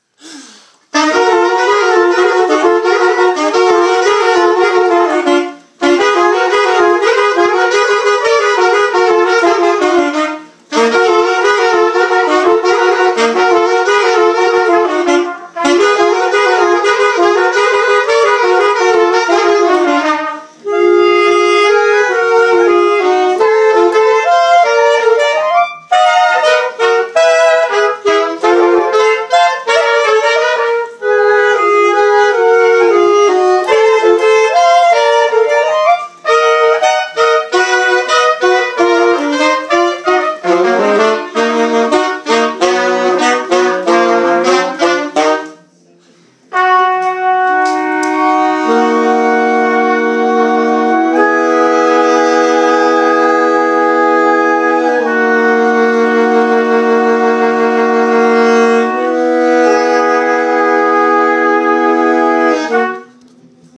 rehearsal recording of melody players